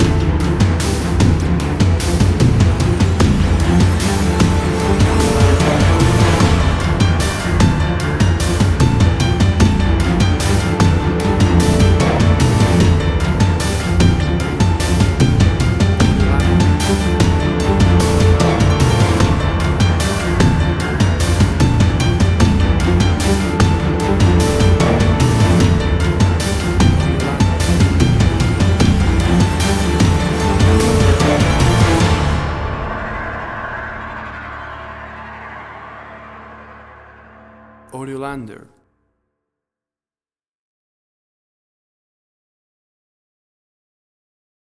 Tempo (BPM): 149